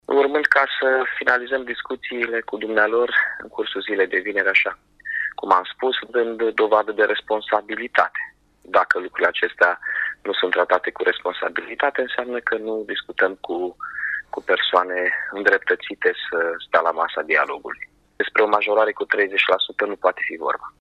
Primarul Iaşului, Mihai Chirica a precizat că momentan nu negociază cu firme private şi doreşte ca şoferii de autobuze să respecte prevederile contractuale: